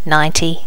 Normalize all wav files to the same volume level.